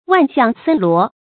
萬象森羅 注音： ㄨㄢˋ ㄒㄧㄤˋ ㄙㄣ ㄌㄨㄛˊ 讀音讀法： 意思解釋： 指天地間紛紛羅列的各種各樣的景象。